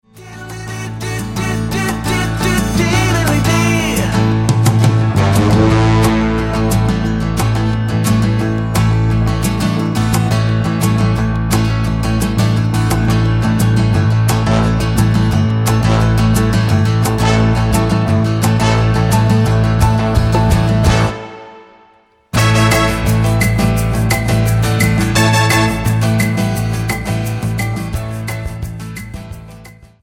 --> MP3 Demo abspielen...
Tonart:A mit Chor